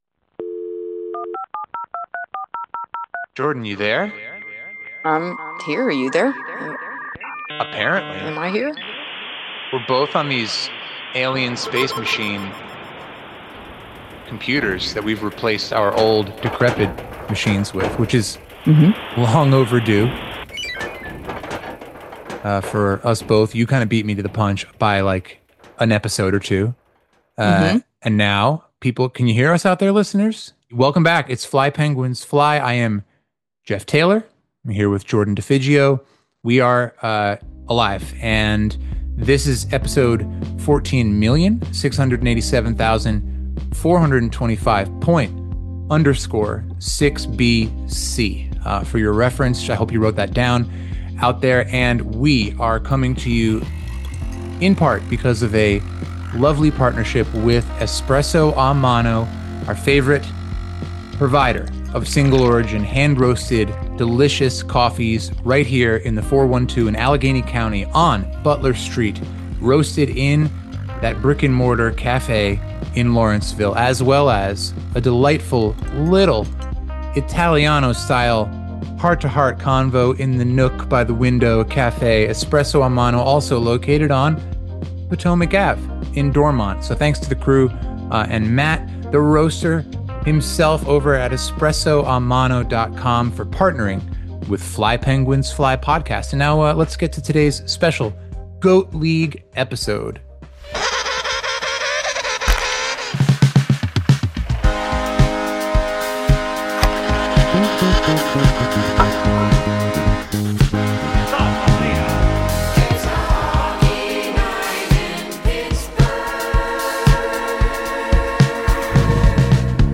Golden G.O.A.T.S. 08/26/25 As the summer winds down and we head into Labor Day weekend, Fly Penguins Fly Podcast takes you out onto the ice, into the locker room, and into the scorekeeper’s box with G.O.A.T. League 4v4 hockey. Today’s episode features chats with some of the league’s most exciting players including Pittsburgh-based OHL’ers, NCAA college hockey commits, and longtime members of the Pens Elite program.